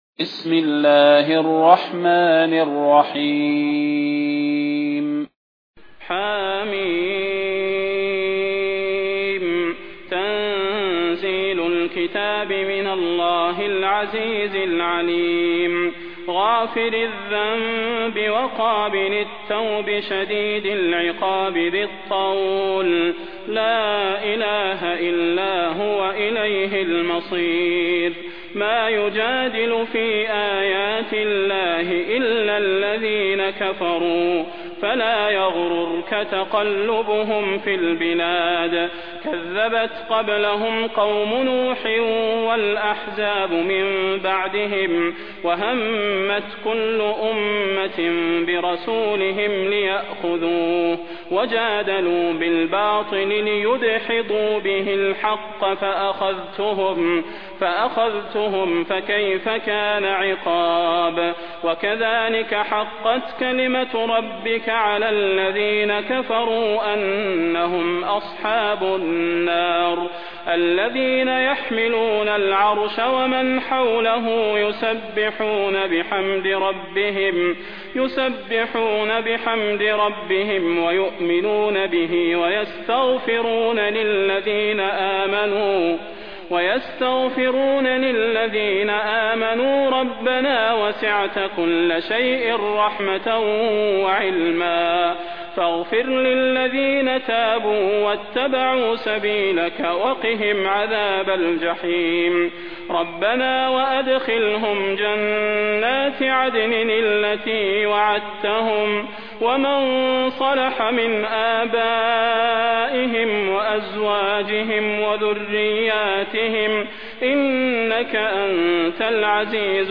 المكان: المسجد النبوي الشيخ: فضيلة الشيخ د. صلاح بن محمد البدير فضيلة الشيخ د. صلاح بن محمد البدير غافر